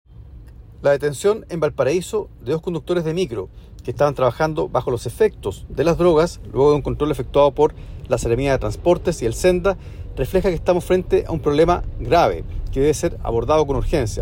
Sobre el procedimiento policial que llevó al arresto de los choferes el día de ayer, el diputado Luis Cuello, comentó la gravedad del problema: